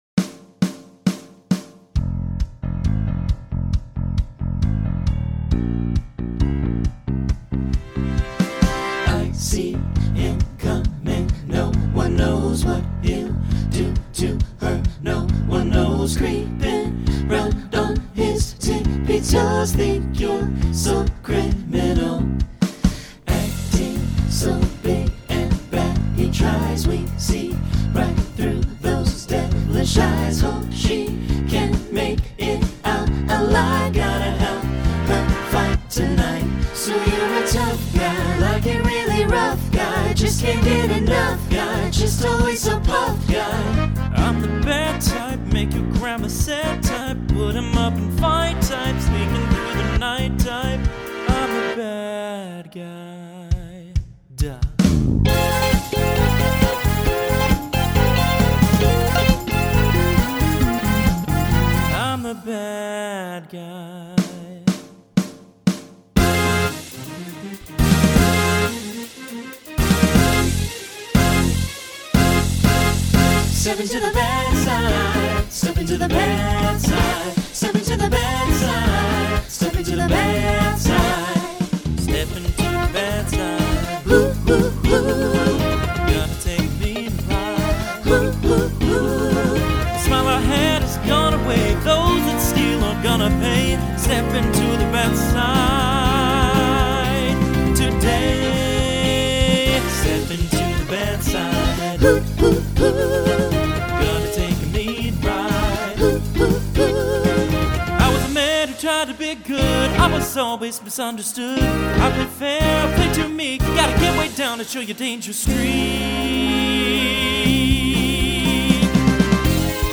Genre Broadway/Film , Rock
Story/Theme Voicing SATB